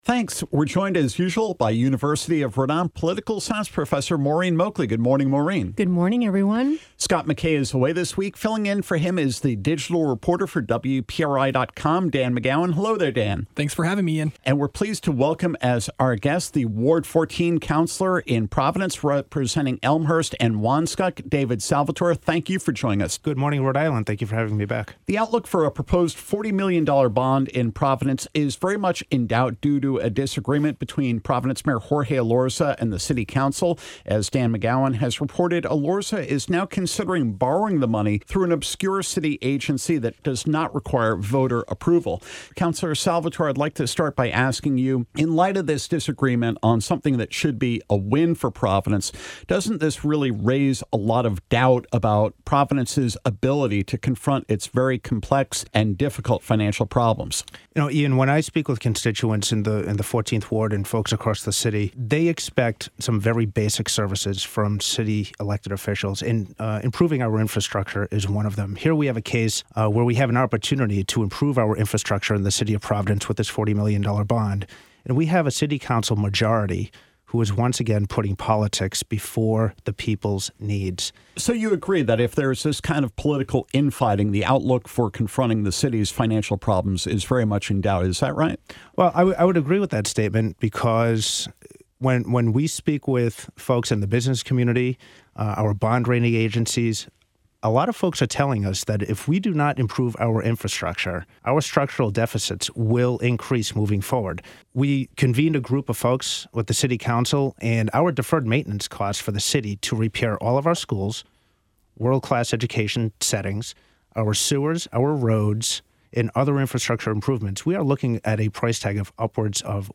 Providence’s Ward 14 City Councilor, David Salvatore (Elmhurst, Wanskuck) joins Political Roundtable to discuss the debate around the city’s $40 million infrastructure bond; the effort to recall Ward 3 Councilor Kevin Jackson; and whether he has any concerns on voting integrity.